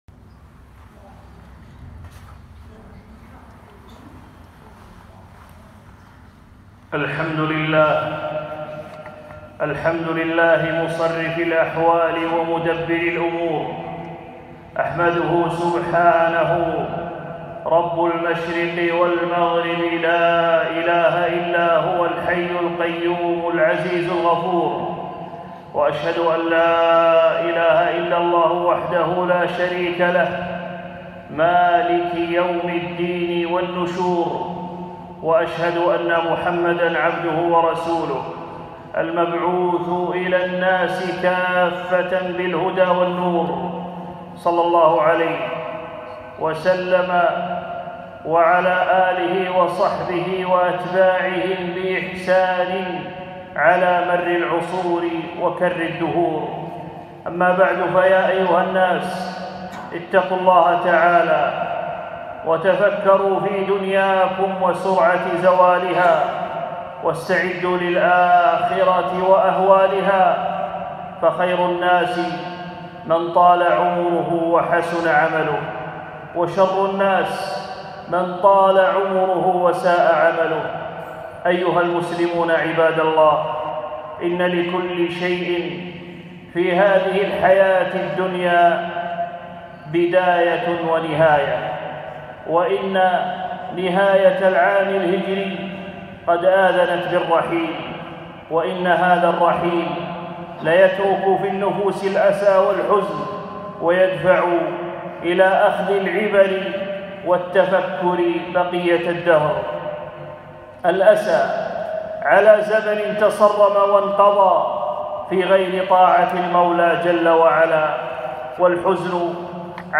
خطبة - نهاية العام الهجري١٤٤٠هــ